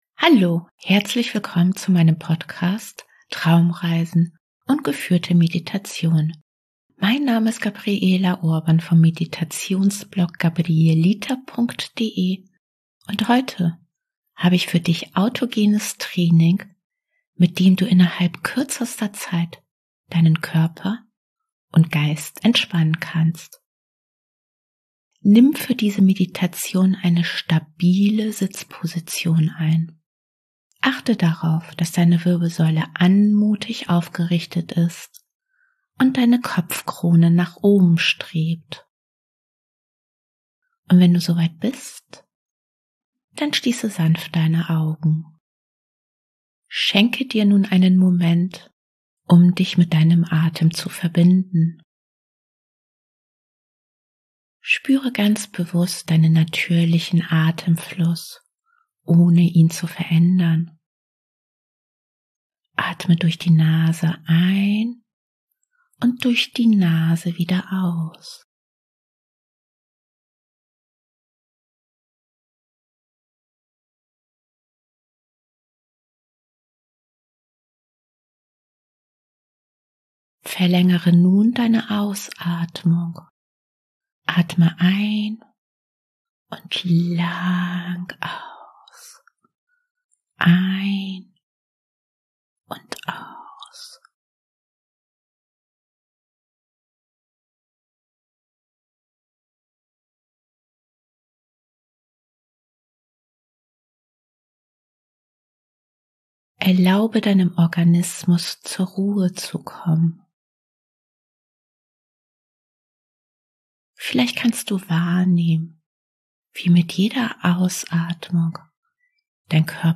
#123: Kurze Entspannung mit Autogenem Training ohne Musik
Traumreisen & geführte Meditationen